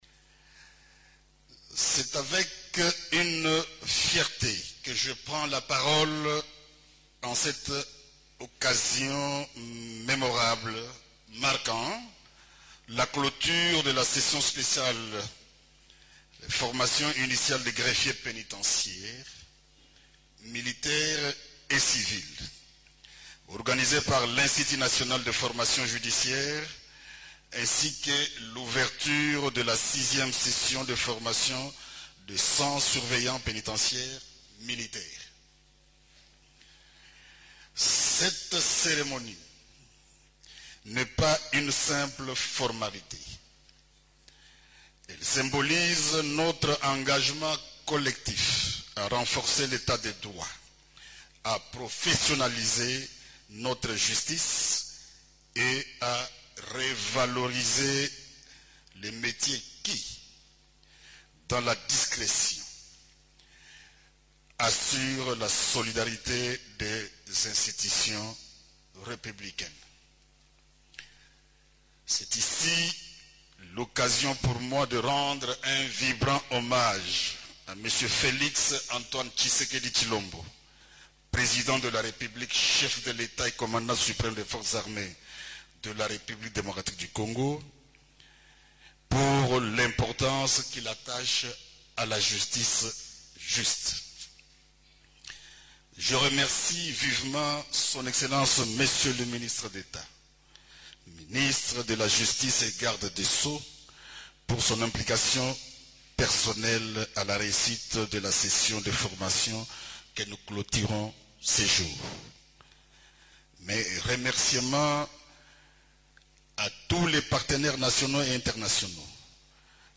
Quarante-deux greffiers pénitentiaires, civils et militaires, dont 16 femmes, ont reçu leurs brevets, jeudi 21 aout, au cours d’une cérémonie, marquant la fin de la 5e session de formation intensive de trois mois, dispensée par l’Institut national de formation judiciaire (INAFORJ).
Les mots du vice-Premier ministre de la Défense nationale: